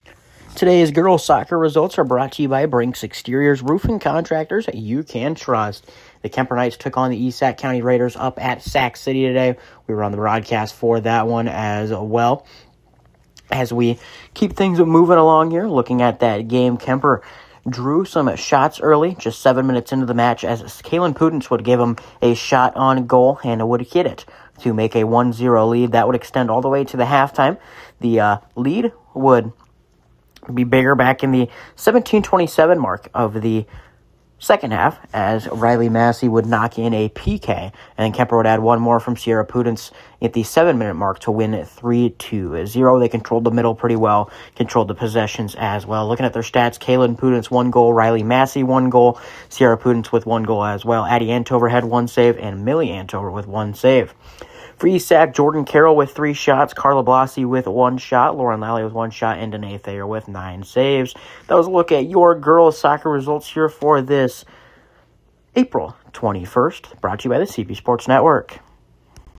Below is an Audio Recap of Girls Soccer Results from Tuesday, April 21st